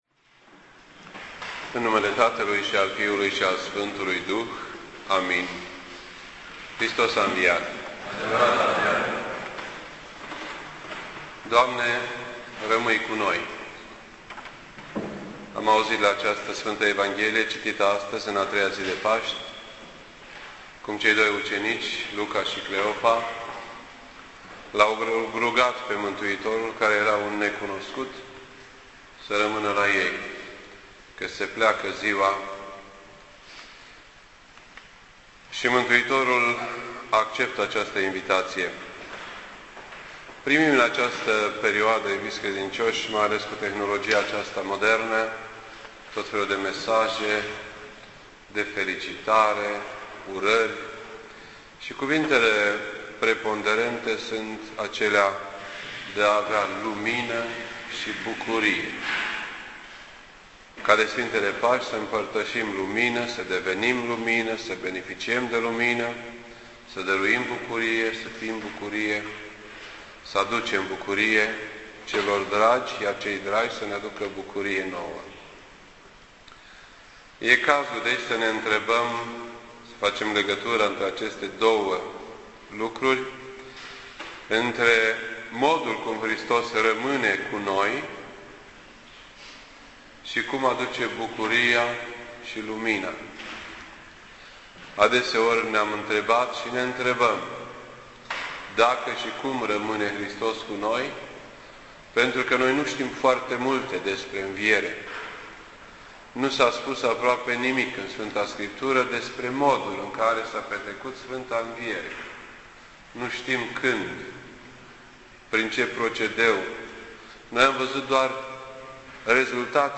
This entry was posted on Tuesday, April 6th, 2010 at 7:35 PM and is filed under Predici ortodoxe in format audio.